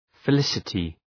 {fı’lısətı}